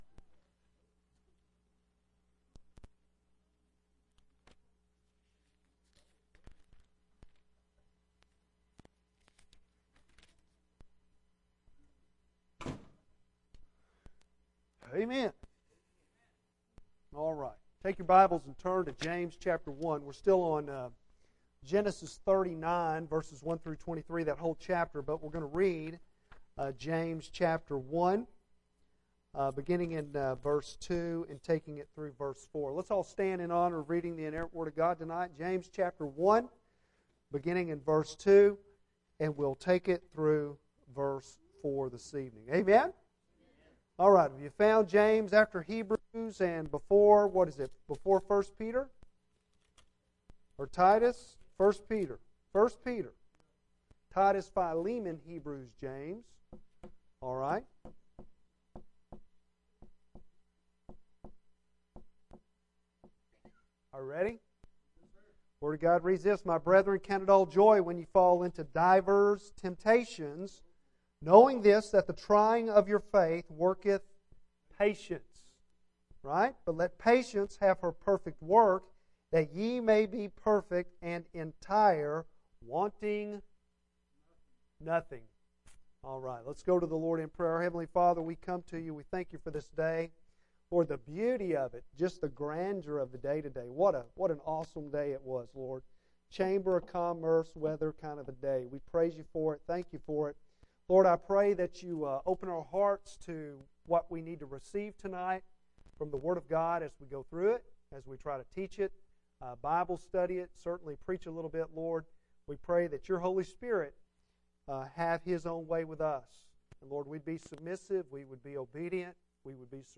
Bible Text: James 1:2-4; Genesis 39:1-23 | Preacher